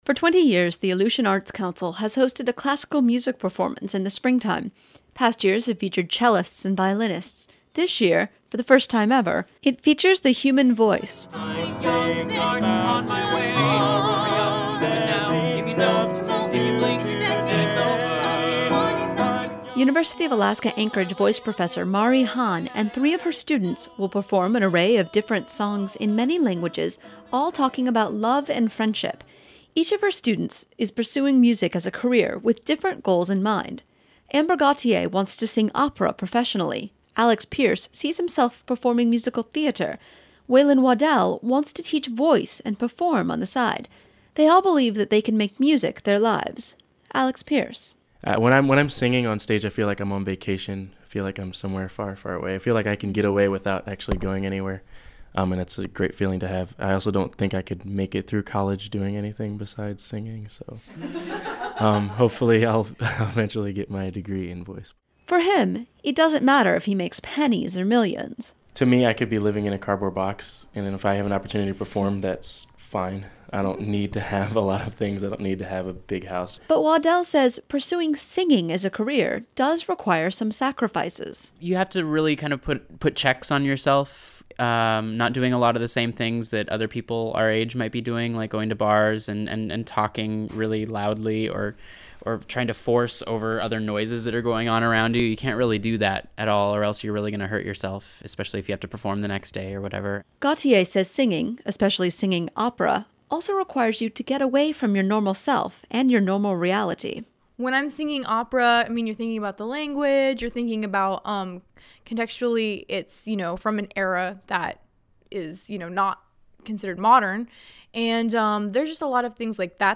Unalaska, AK – The Aleutian Arts Council hosts a classical music concert every spring. This year's featured artists are voice majors from the University of Alaska-Anchorage.